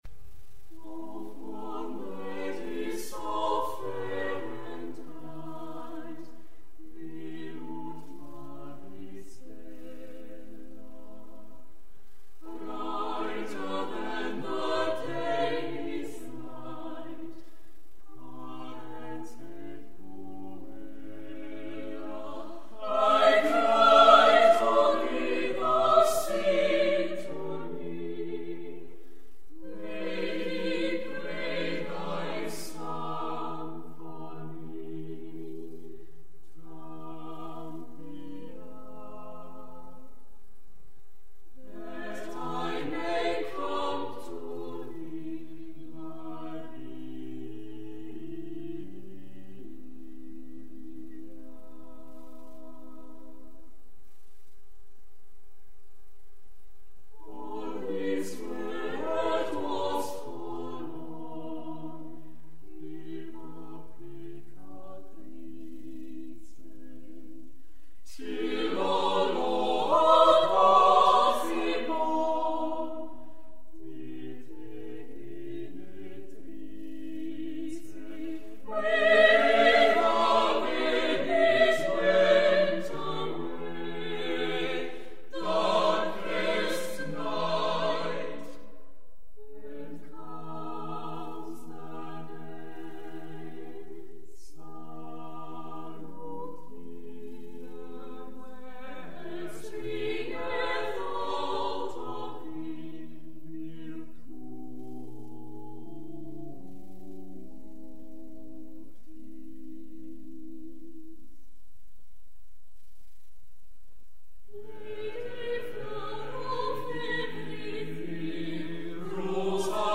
Das neunk�pfige junge Vokalensemble milagro aus Karlsruhe singt geistliche und weltliche A-cappella-Werke von Renaissance bis Moderne.
SWR-Rundfunkaufzeichung